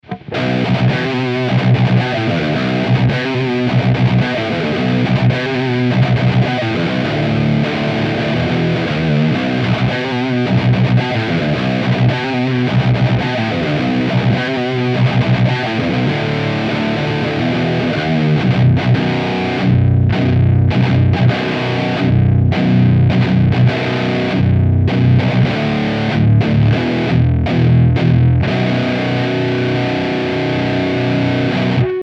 - Canal lead, Fat 8, Depth 3, Presence 6 :
Les EQ sont à midi, et le gain du lead à 2-3 sur tous les samples.
Evil Eddie_Lead 5 V8 D3 P6.mp3